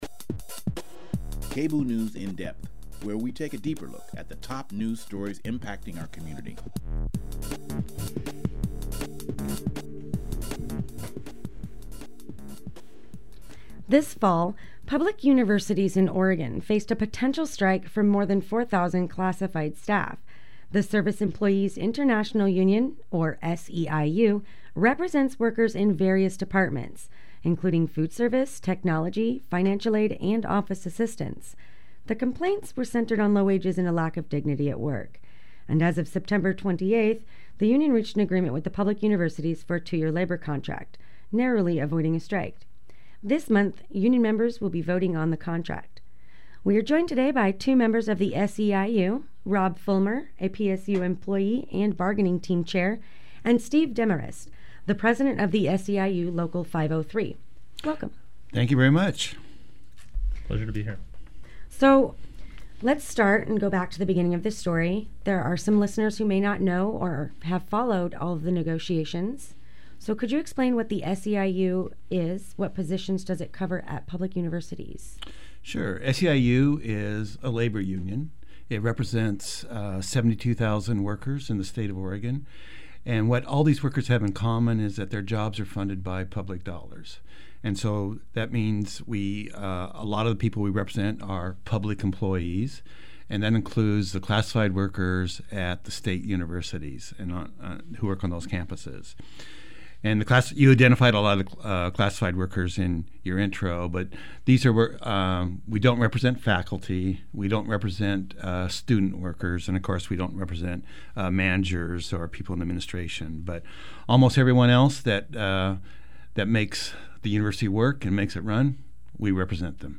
join us in studio to discuss the contract negotiation, the narrowly avoided strike, and what's next.